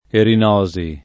[ erin 0@ i ]